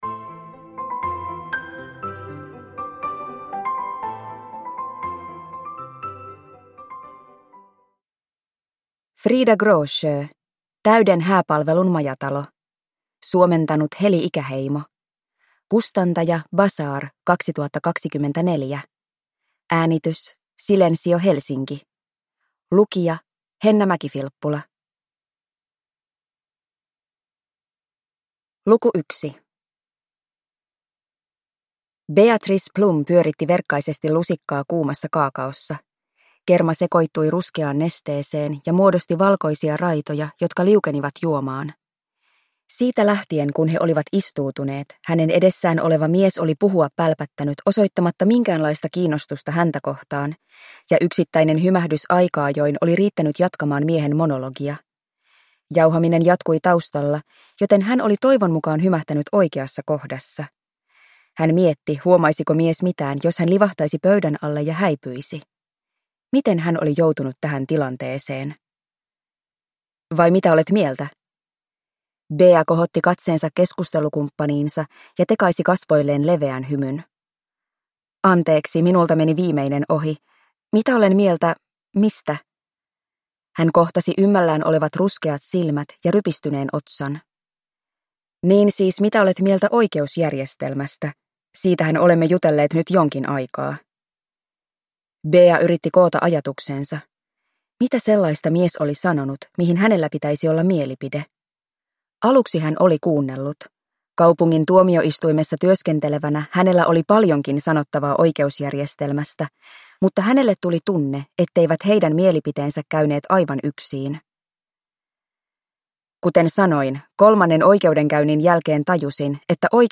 Täyden hääpalvelun majatalo (ljudbok) av Frida Gråsjö | Bokon